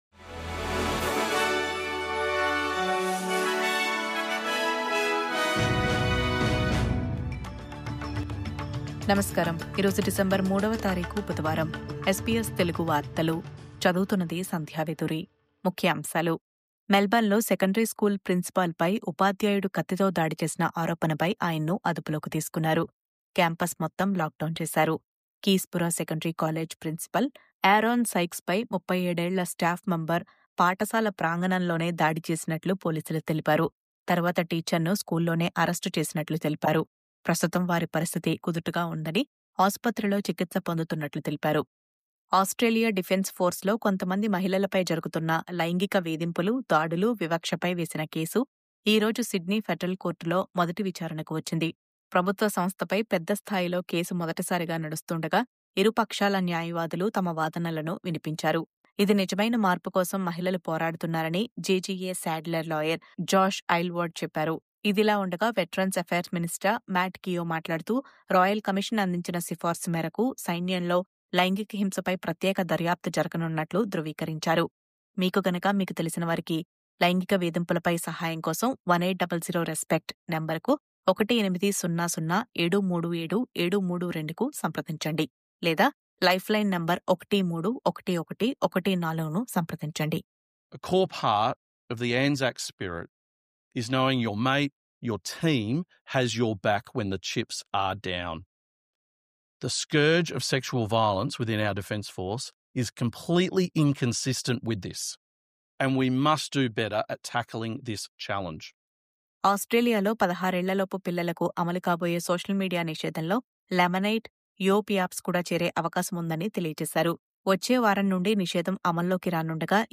News update: ఆస్ట్రేలియా డిఫెన్స్ ఫోర్స్‌లో లైంగిక వేధింపుల ఆరోపణలు… విచారణ ప్రారంభం